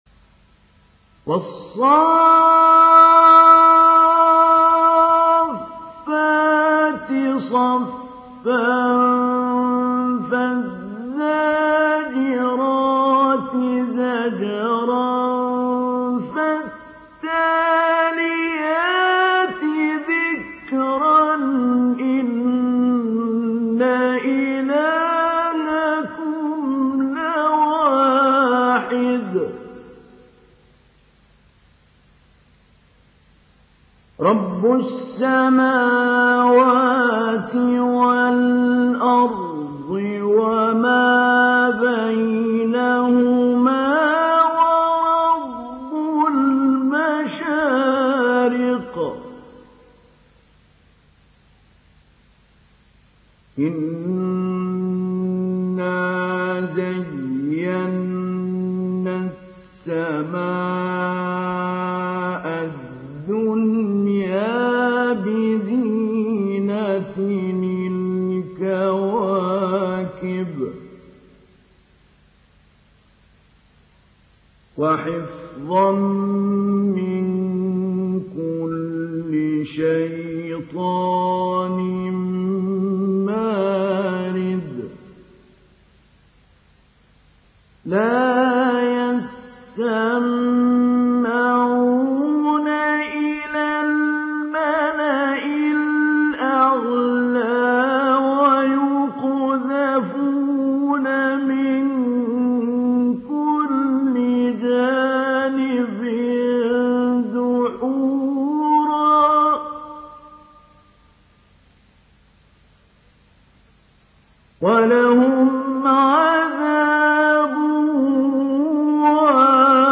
ডাউনলোড সূরা আস-সাফ্‌ফাত Mahmoud Ali Albanna Mujawwad